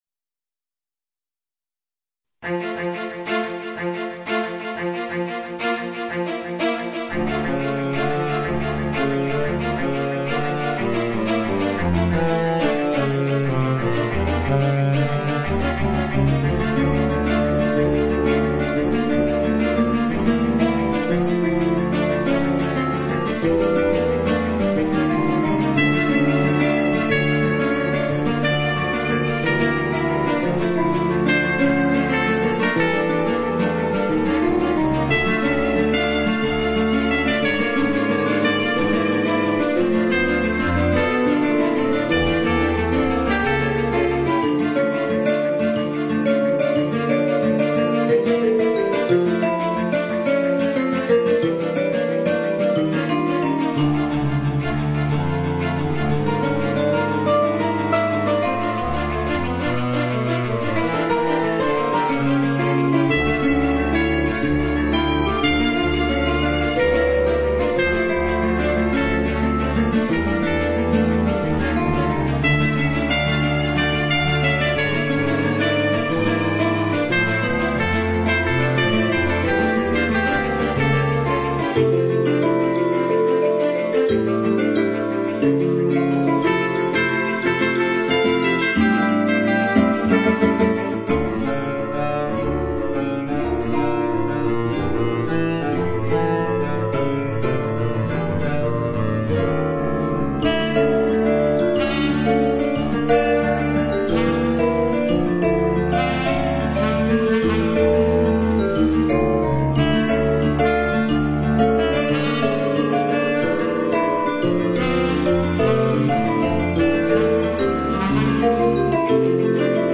- CHAMBER MUSIC -
for Sax-Sop (alto), Guitar, Cello and Keyboards (Vibes-Strings)
( sax -ch-vc- keyboard)